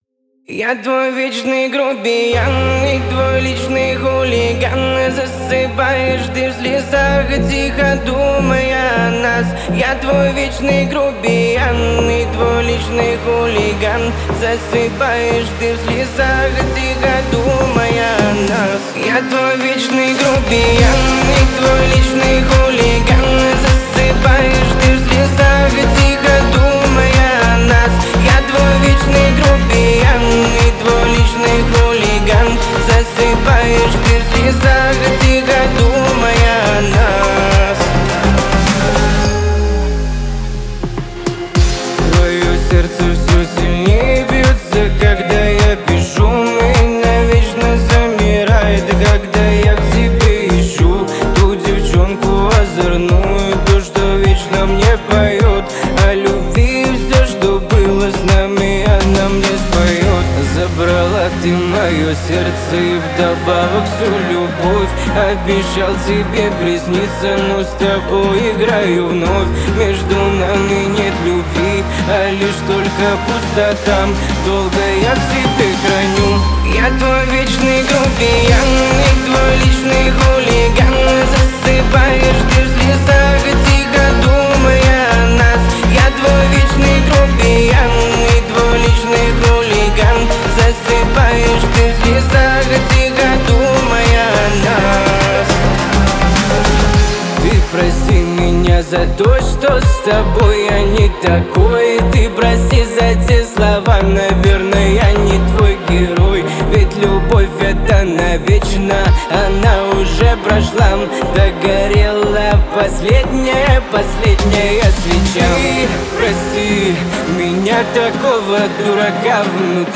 Танцевальная музыка
dance песни